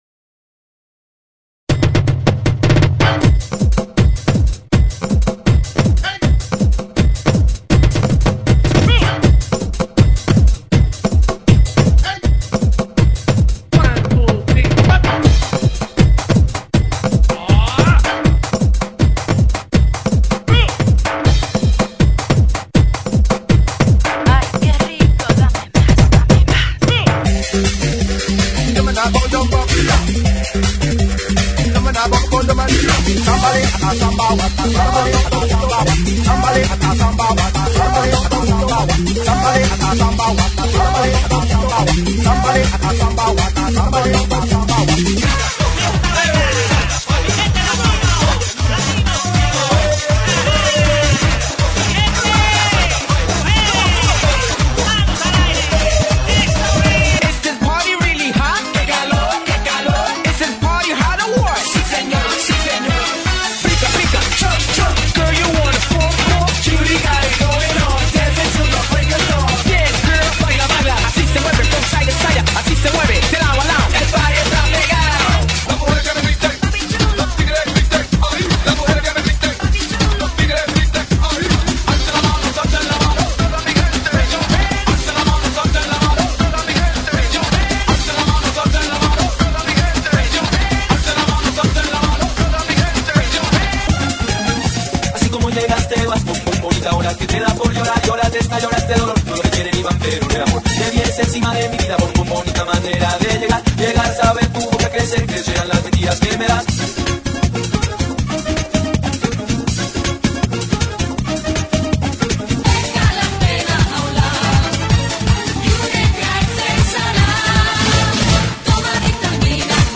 GENERO: LATINO – REMIX